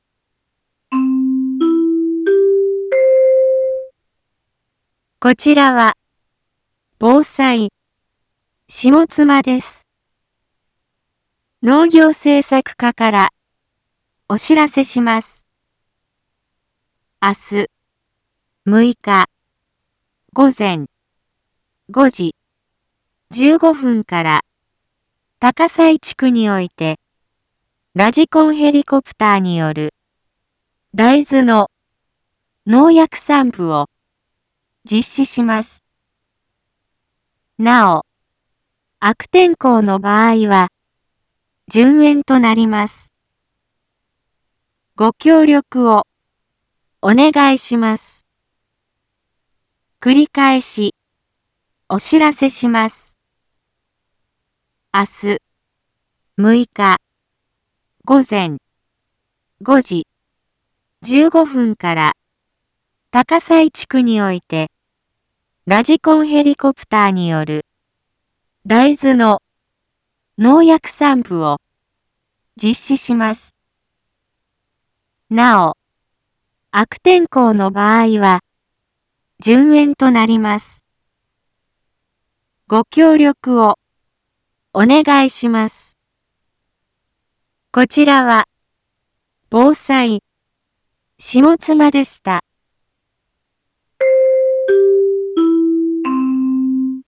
Back Home 一般放送情報 音声放送 再生 一般放送情報 登録日時：2025-08-05 12:31:54 タイトル：大豆の無人ヘリによる空中防除 インフォメーション：こちらは、ぼうさいしもつまです。